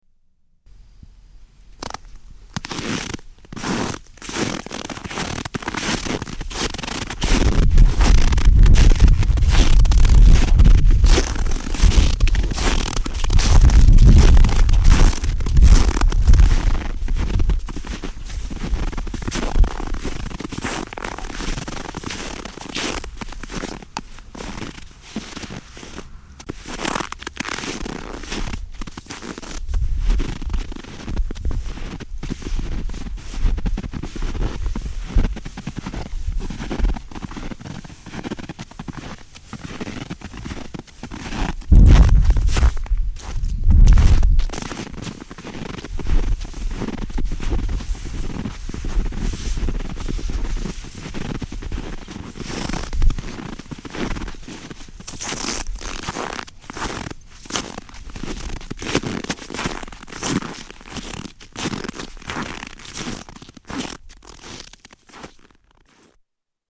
Walking in snowy lakes and forrests